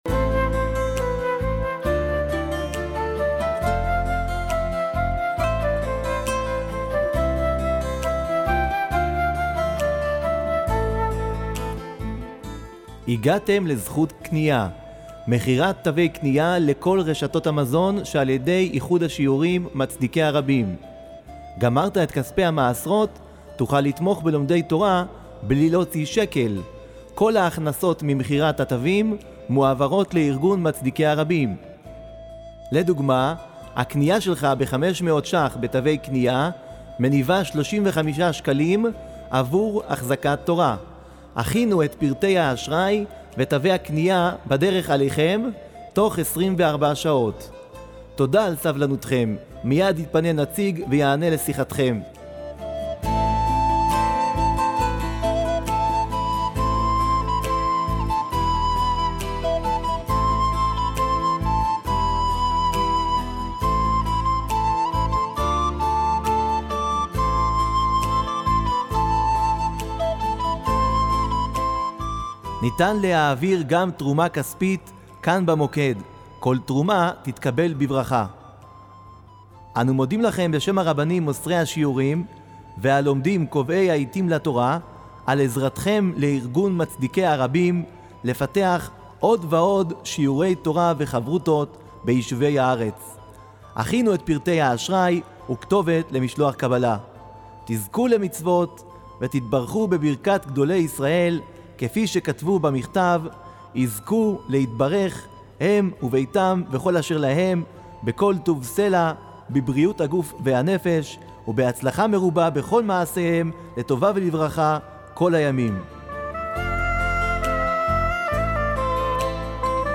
תשדיר לזמן ההמתנה בטלפון
תשדיר עבור ההמתנה בטלפון זכות קניה.mp3